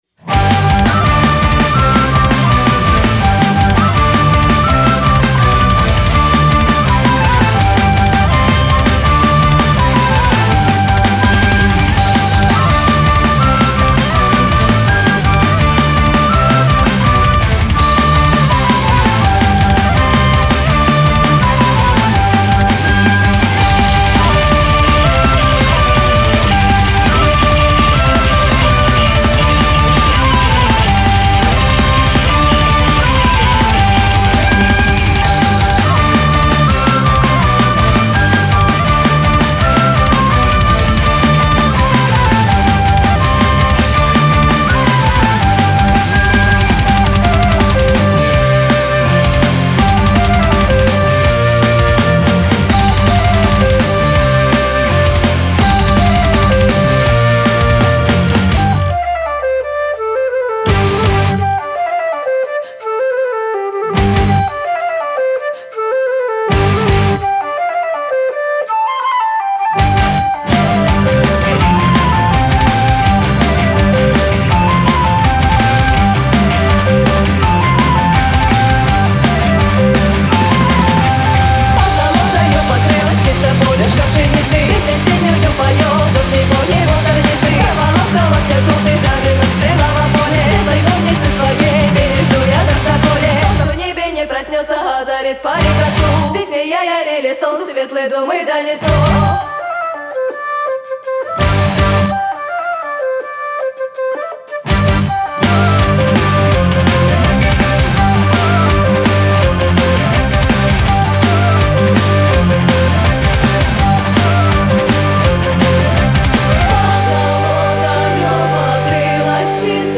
заводная, скоростная
вокал, клавиши
флейта, волынка, вокал
ударные
бас, гроул, калюка
гитара